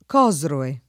Cosroe [ k 0@ roe ]